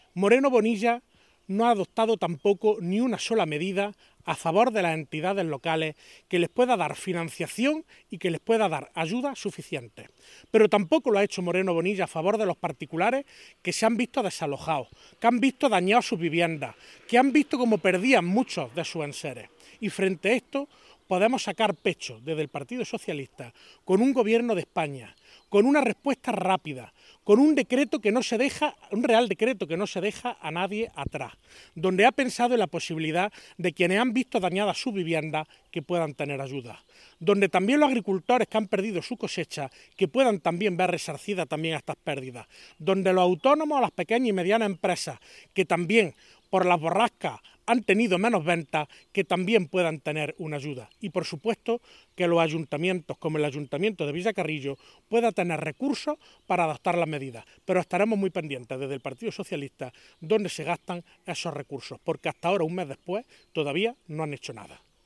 El parlamentario hizo estas declaraciones en el transcurso de una visita a la zona afectada